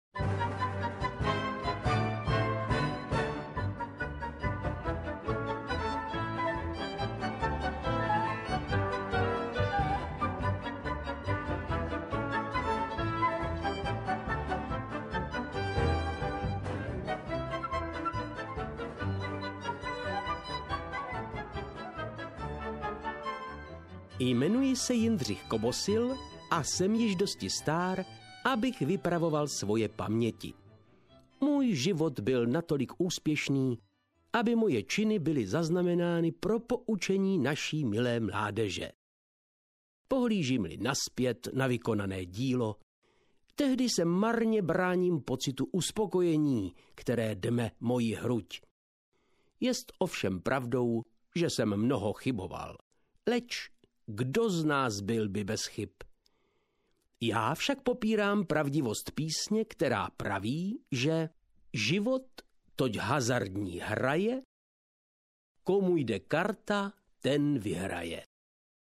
Hráči audiokniha
Ukázka z knihy
• InterpretVáclav Knop